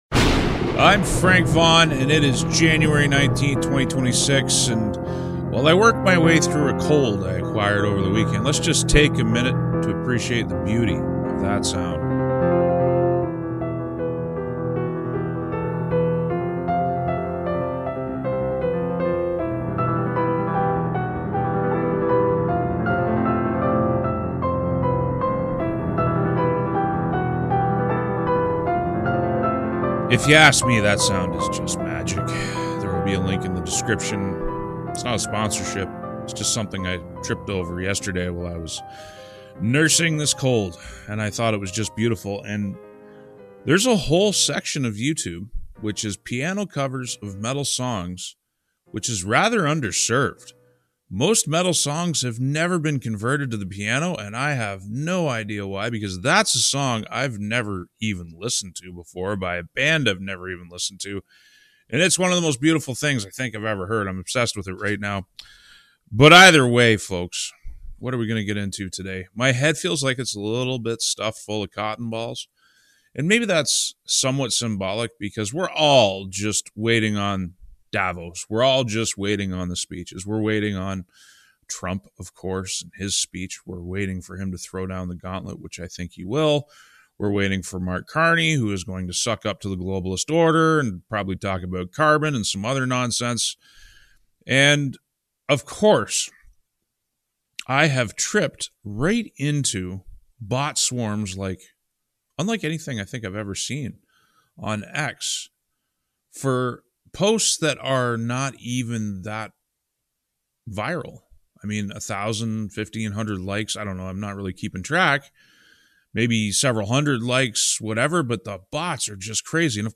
Forgive me as I navigate this video with a head thick with a cold.